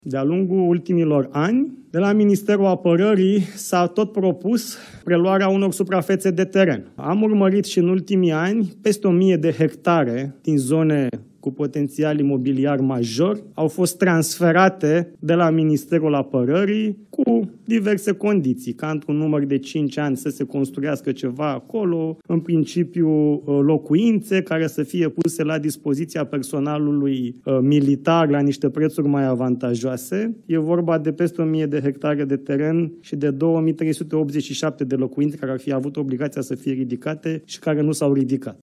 Ministrul Apărării, Radu Miruță: „Peste o mie de hectare cu potențial imobiliar major au fost transferate de la Ministerul Apărării”